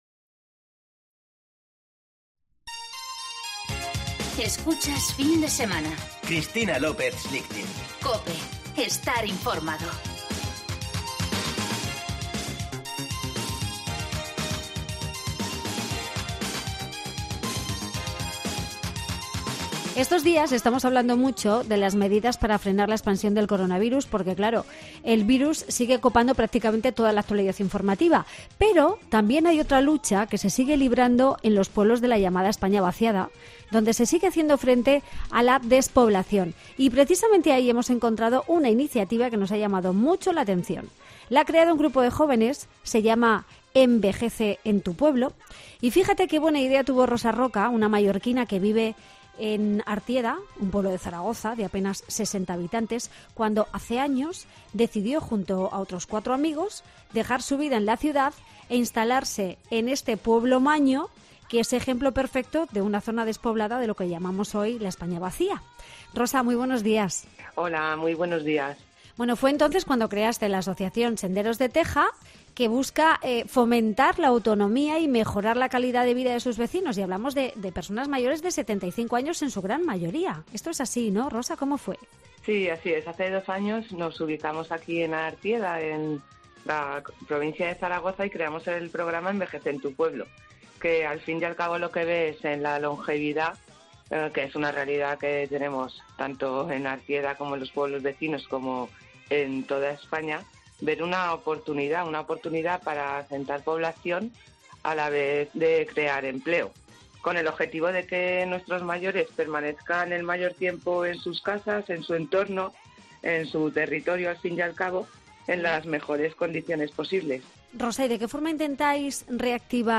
En Huerta del Marquesado, un municipio de Cuenca con 200 vecinos, el Ayuntamiento puso en alquiler una panadería -con un horno tradicional de leña- por 100 euros, con la finalidad de atraer nuevas familias a la localidad. Hablamos con Ana Castillero, teniente alcalde de Huerta del Marquesado y la encargada de realizar la selección de la familia que se quedará con el negocio.